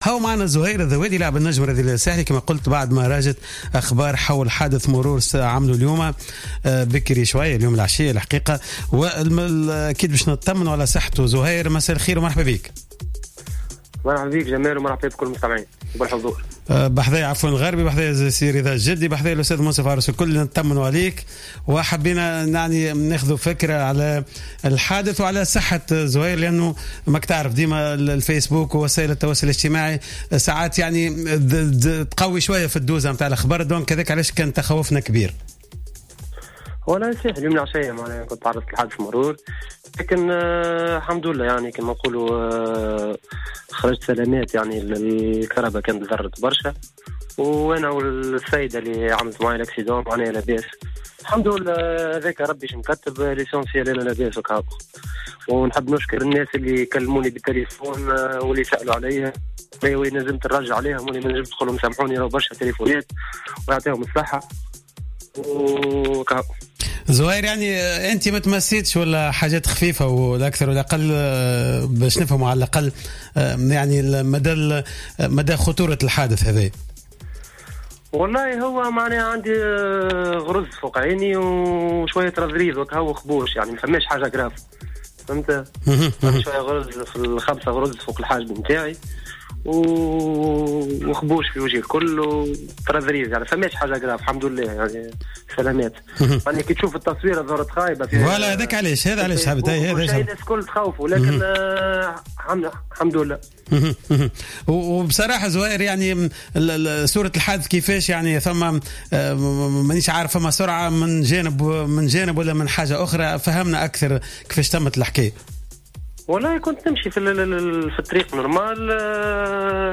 أكد لاعب النجم الساحلي زهير الذوادي في مداخلة في حصة "قوول" أنه قد خرج من حادث المرور الذي تعرض له عشية اليوم في جهة المنار بالعاصمة دون أي أضرار بدنية رغم الخسائر الجسيمة التي لحقت السيارة .